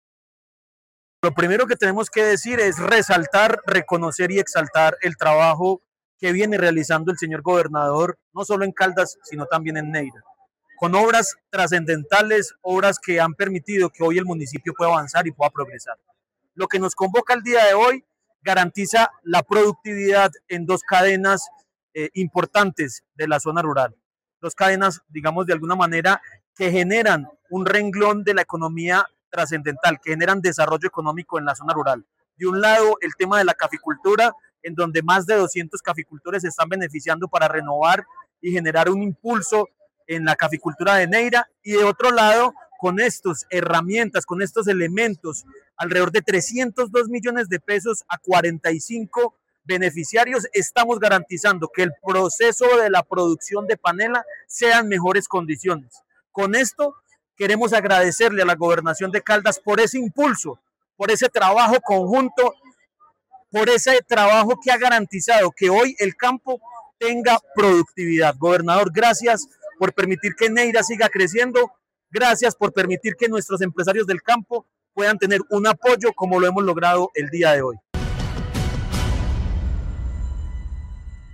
Jhon Jairo Castaño Flórez, alcalde de Neira